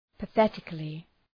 Προφορά
{pə’ɵetıklı}
pathetically.mp3